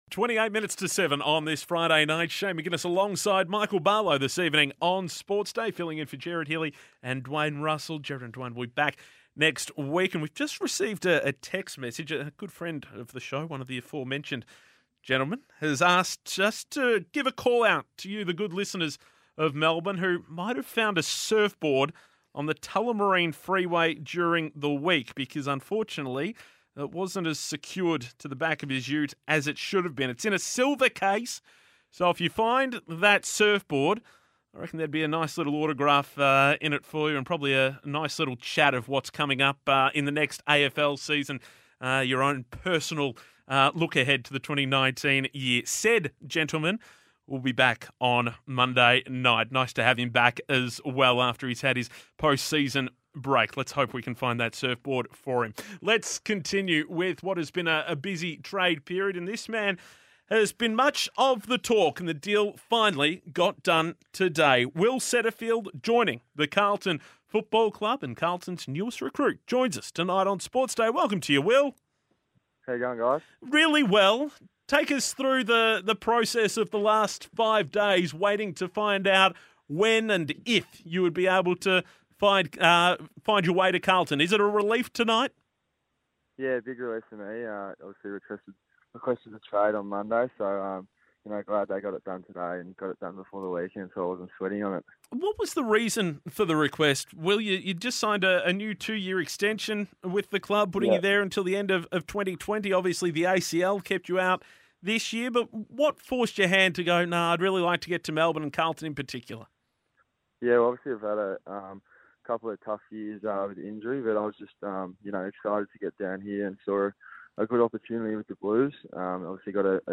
New Blue Will Setterfield speaks to 3AW about his move to Carlton from GWS.